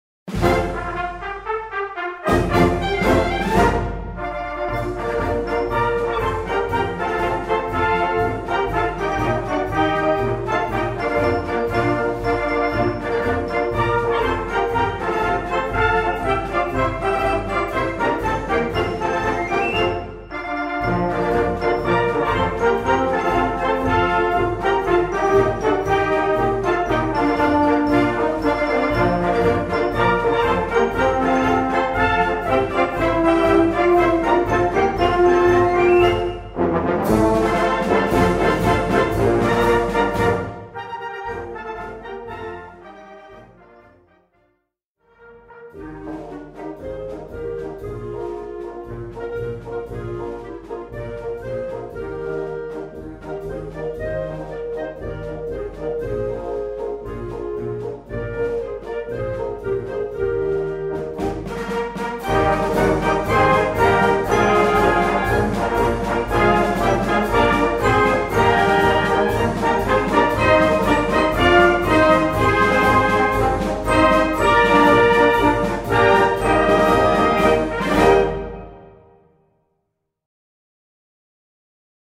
2:30 Minuten Besetzung: Blasorchester PDF